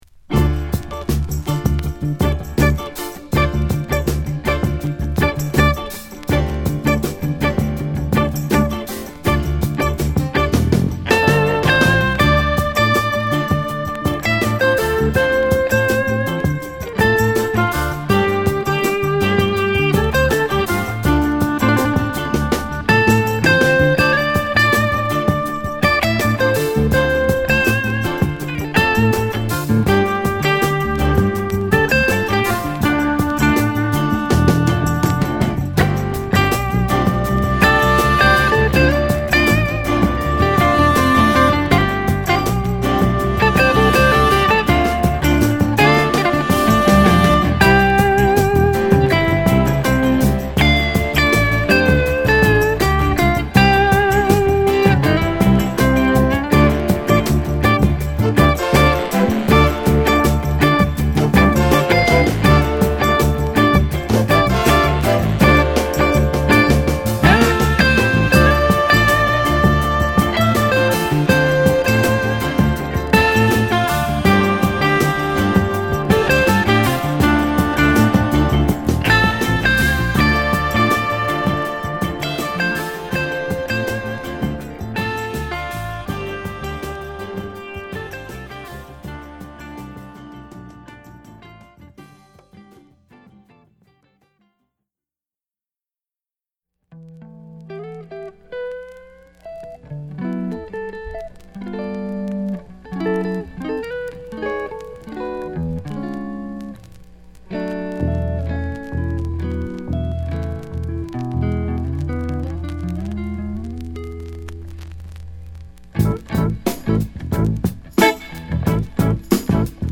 ＊チリノイズ有りの為スペシャルプライス。